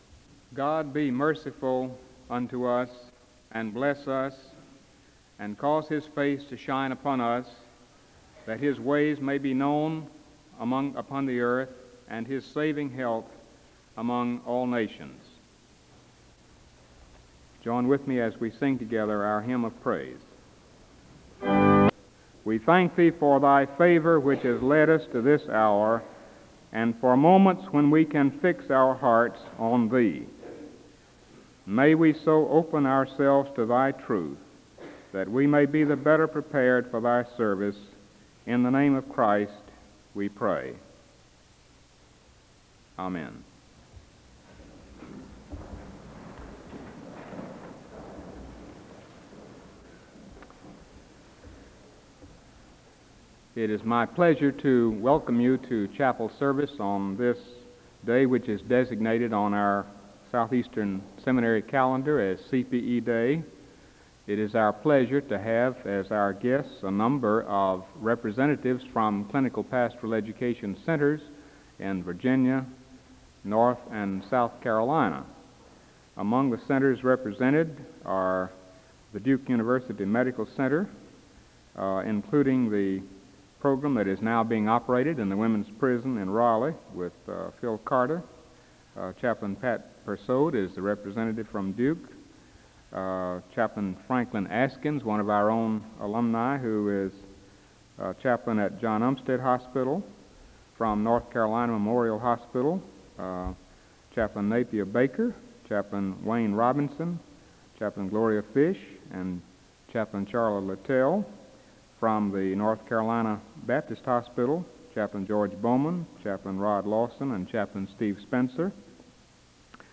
Home SEBTS Chapel